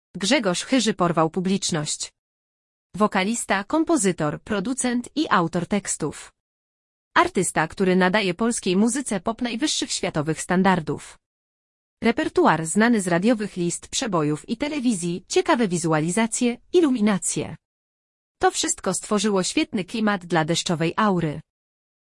To wszystko stworzyło świetny klimat dla deszczowej aury.